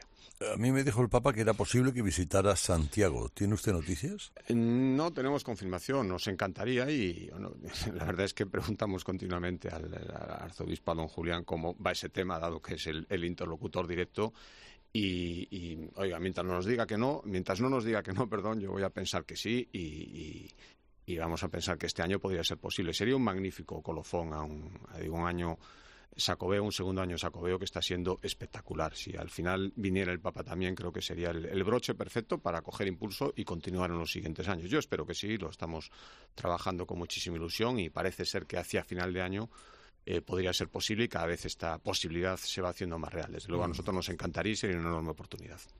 El presidente de la Xunta de Galicia ha hablado en 'Herrera en COPE' sobre varios temas incluida una posible visita del Santo Padre a la capital gallega
El nuevo presidente de la Xunta de Galicia, Alfonso Rueda, ha sido entrevistado este martes por Carlos Herrera y entre los muchos temas abordados también ha estado presente una posible visita del Papa Francisco a Santiago de Compostela.